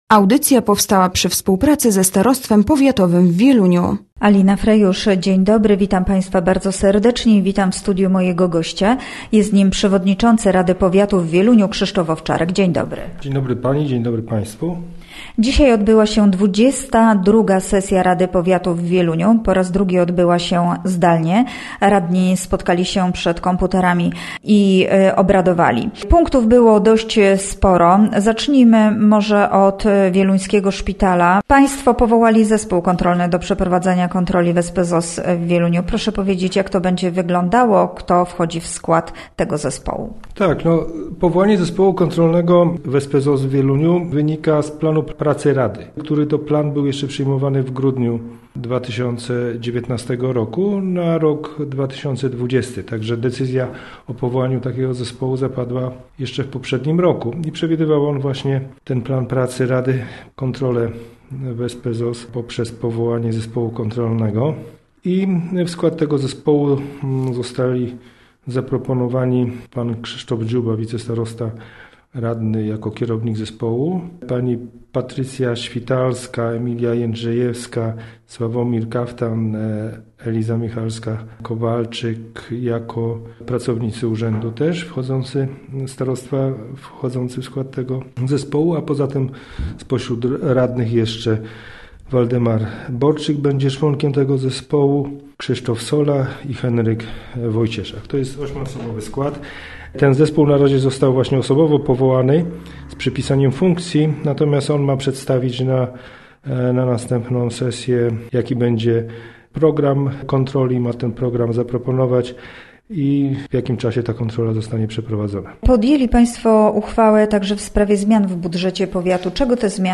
Gościem Radia ZW był Krzysztof Owczarek, przewodniczący Rady Powiatu w Wieluniu